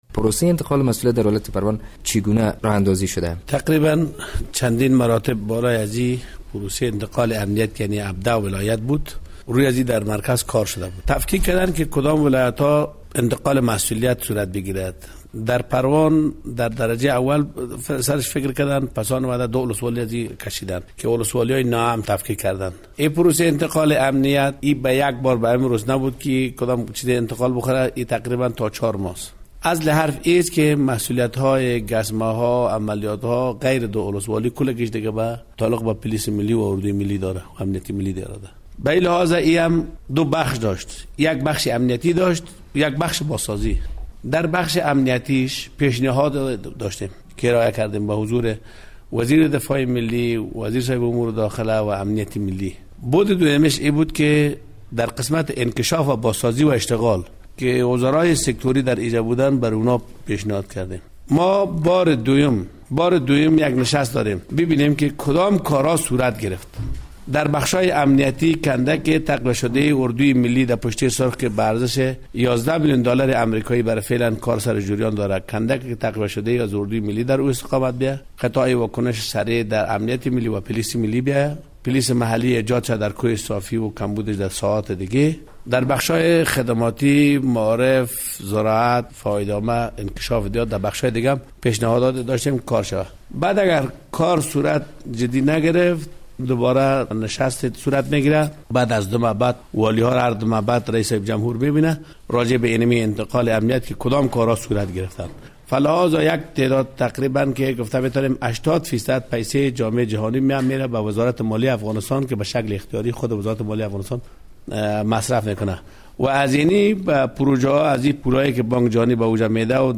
مصاحبه با والی پروان درمورد مرحلهء دوم انتقال مسوولیت های امنیتی